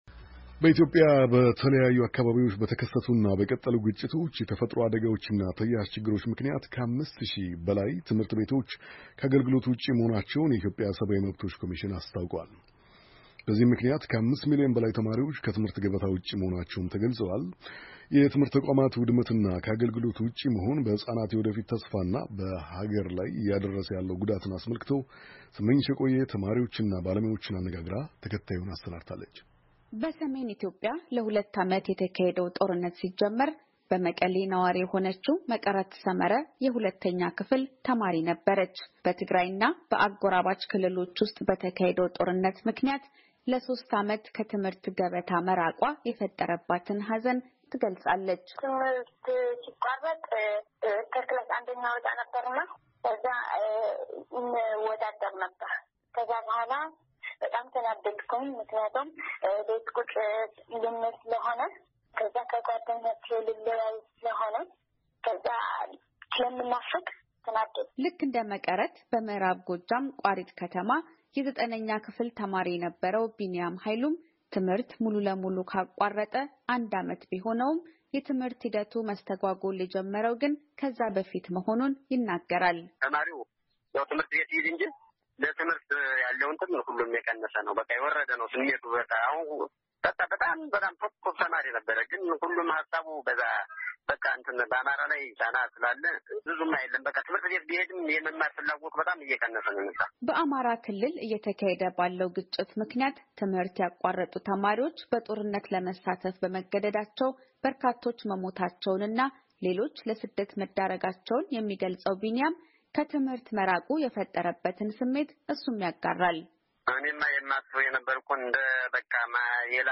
ተማሪዎችንና ባለሞያዎችን አነጋግራ ተከታዩን ዘገባ አዘጋጅታለች።